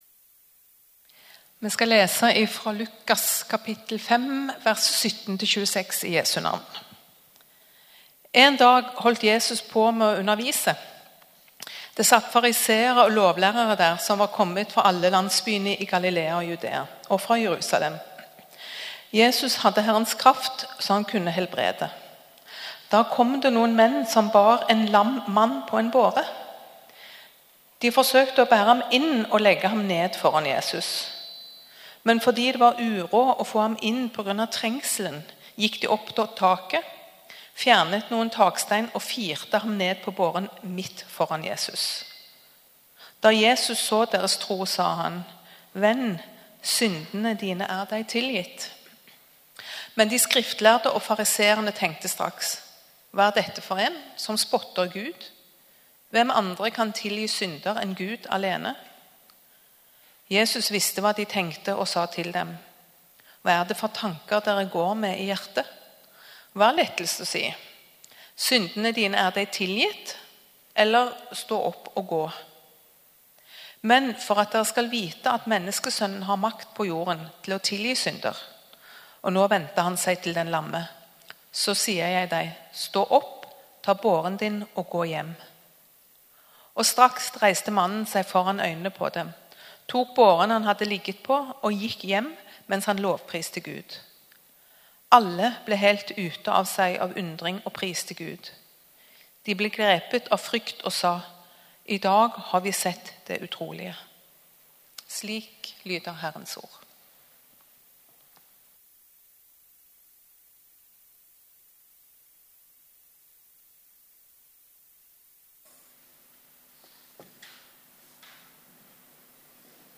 Gudstjeneste 29. august 2021, et livet i fellesskapet | Storsalen